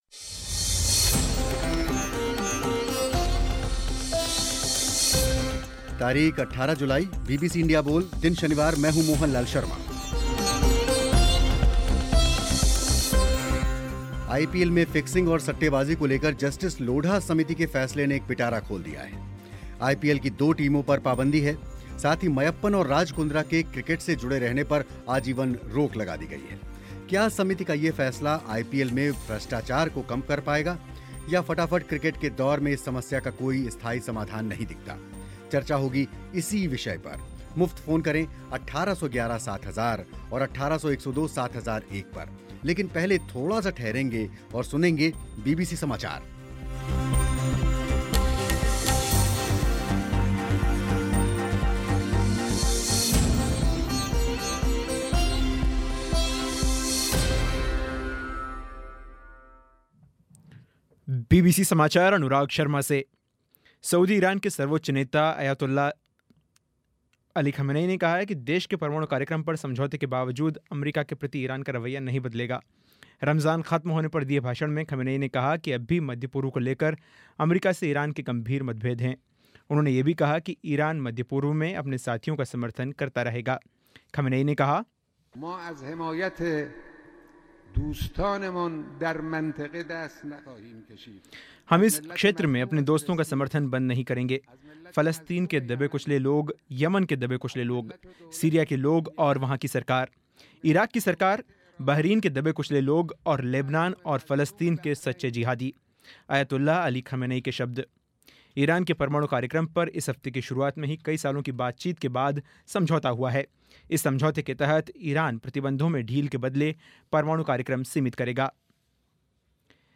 क्या समिति का ये फ़ैसला आईपीएल में भ्रष्टाचार को कम कर पाएगा या फटाफट क्रिकेट के दौर में इस समस्या का कोई स्थायी समाधान नहीं दिखता. बीबीसी इंडिया बोल में चर्चा हुई इसी विषय पर